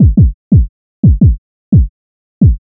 • Phonk Type Kick Loop - 808 E.wav
Hard punchy kick sample for Memphis Phonk/ Hip Hop and Trap like sound.